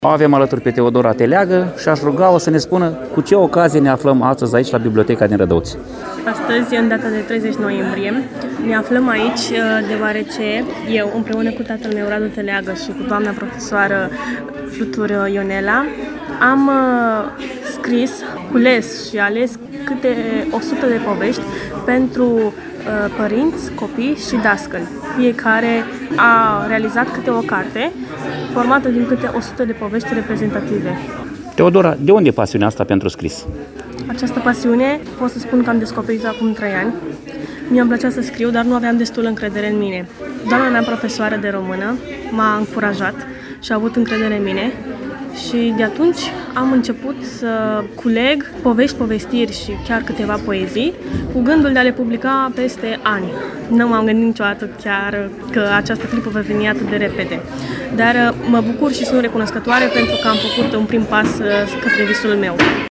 Pe 30 noiembrie, la Biblioteca Tudor Flondor din Rădăuți au avut loc o serie de lansări de carte.